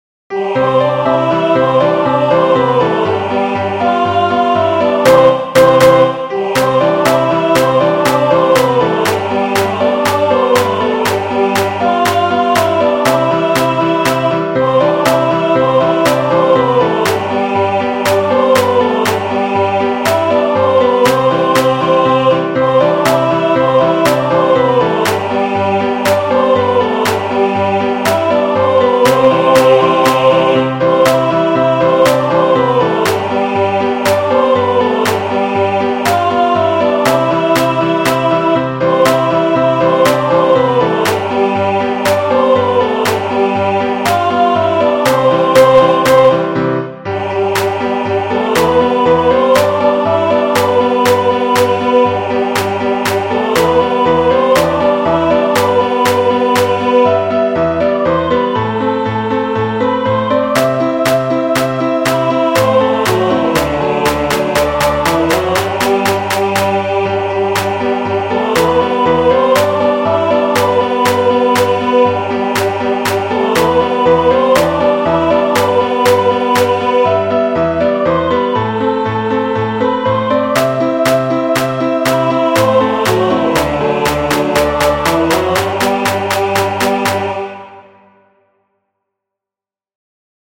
3  MARCHAS POPULARES para 2026
Alegre, ritmo  cativante,entra facilmente no ouvido e é convidativa ao acompanhamento.